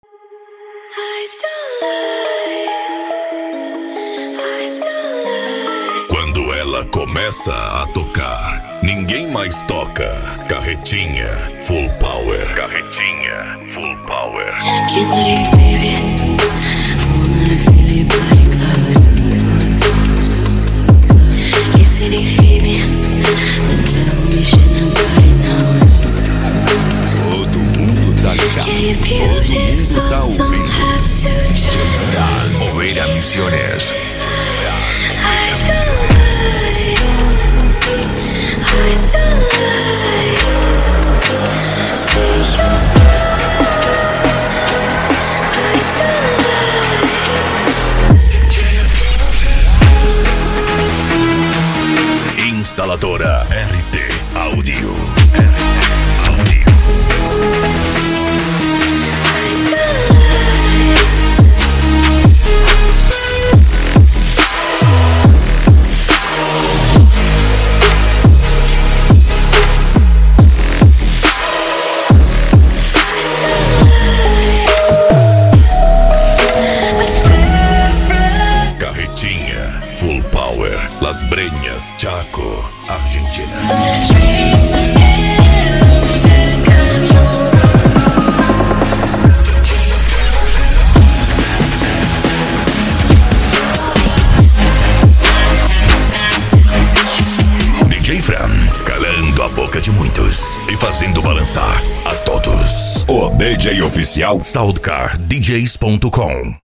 PANCADÃO
Remix